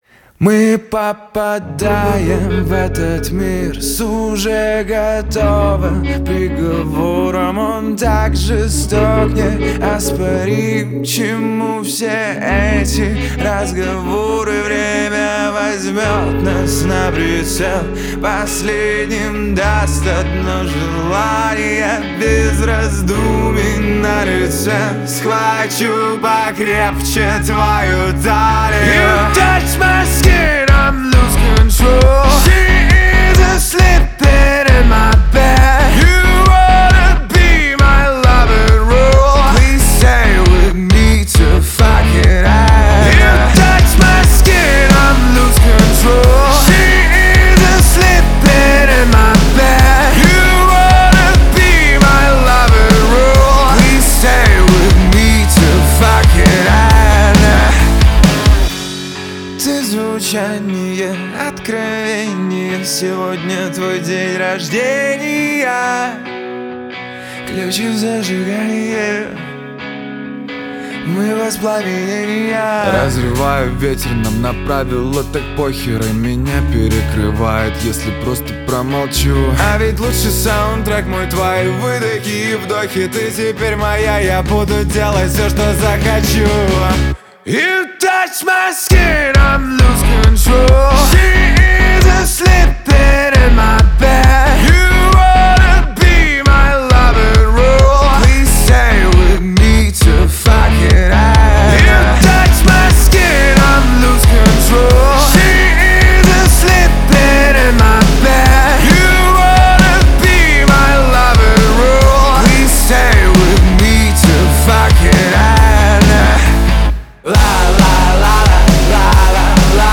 Русские поп песни, 2025